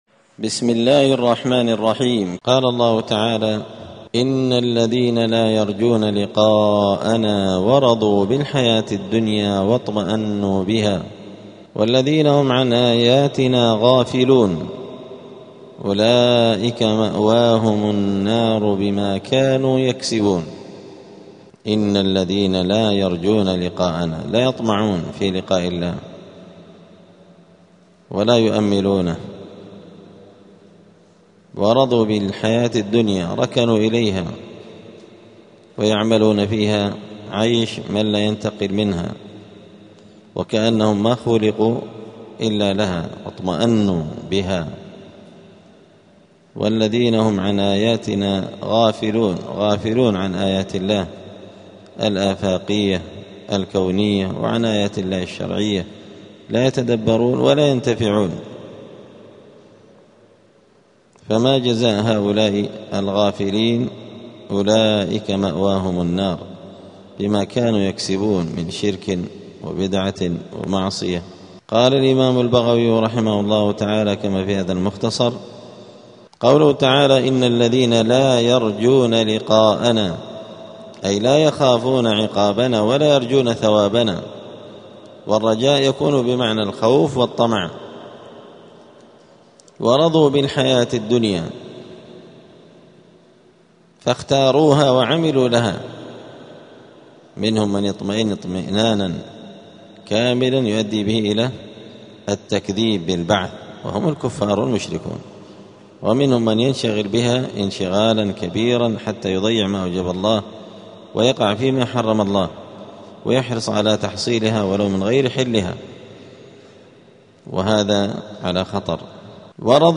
📌الدروس اليومية